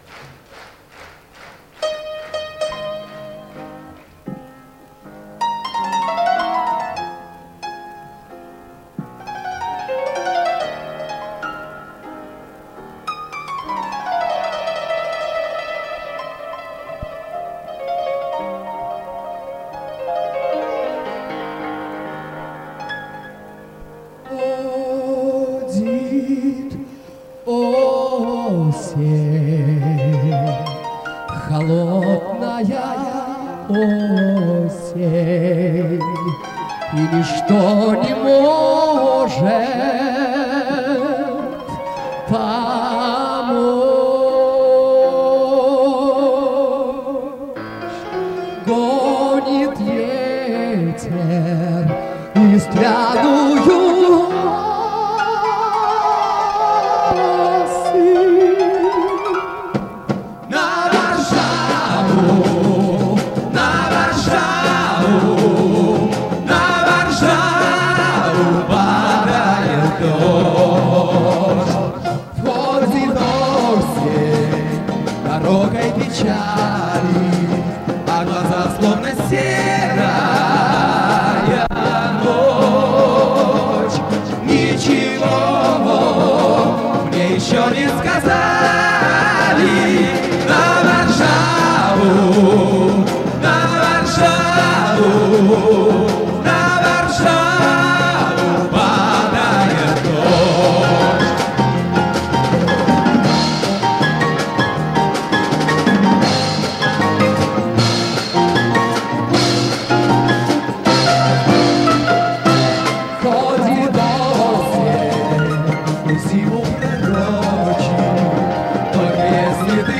с концерта